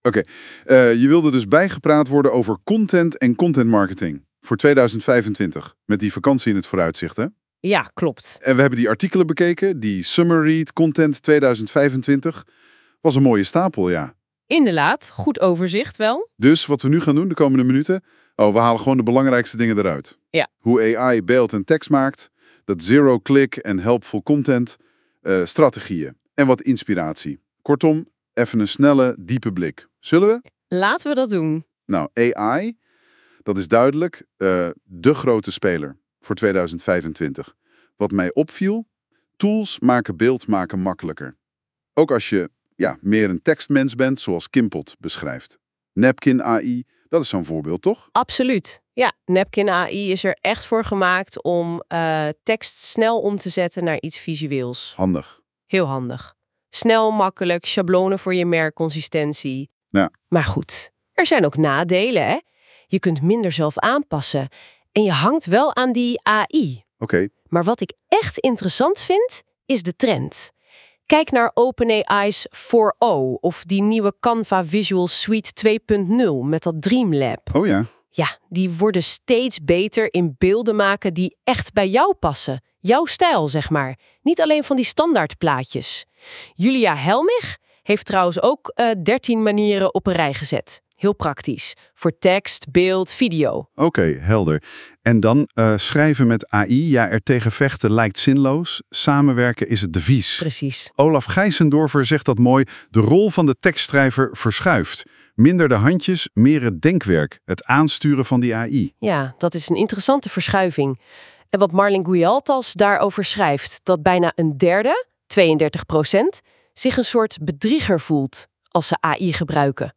Luister je liever dan je leest? Laat je door 2 AI-hosts in 6 minuten bijpraten over deze summerread, gegenereerd door NotebookLM.